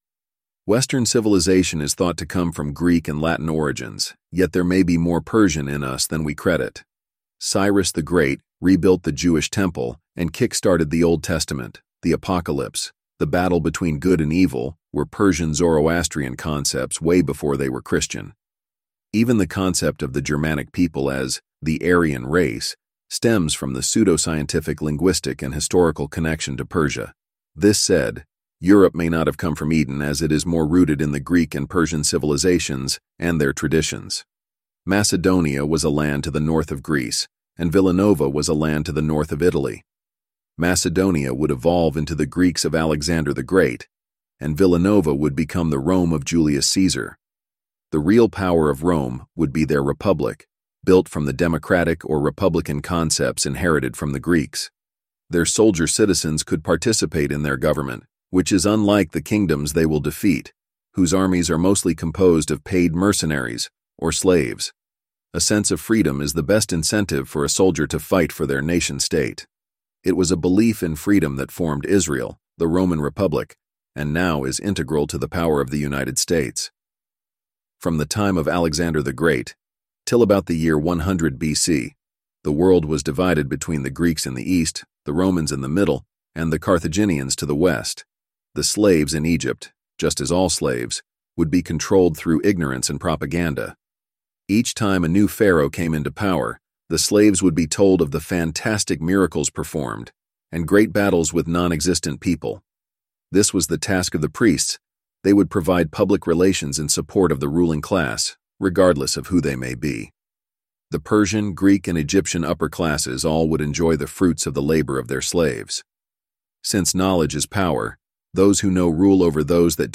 5- Greece and Rome 9-READ-ALOUD-18m-17s